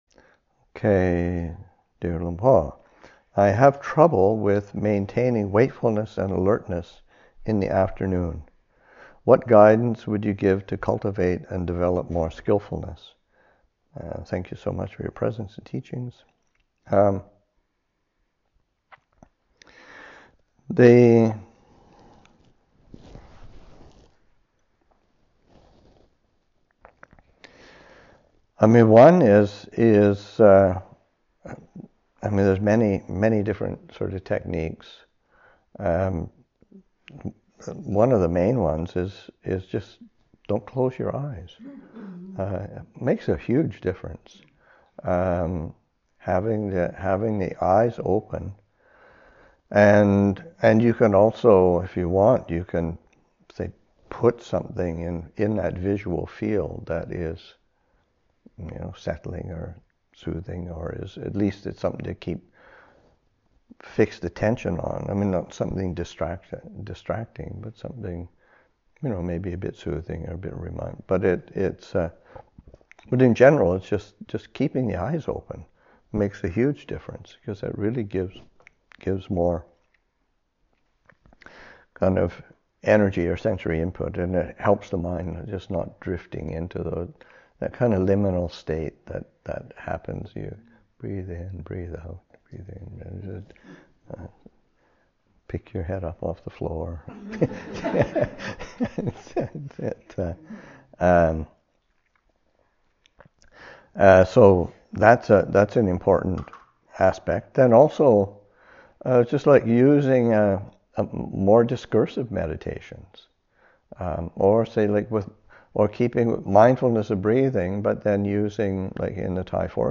Madison Insight Retreat 2023, Session 2 – Oct. 14, 2023